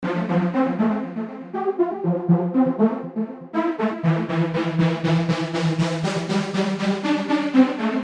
标签： 120 bpm Electronic Loops Synth Loops 1.35 MB wav Key : Unknown
声道立体声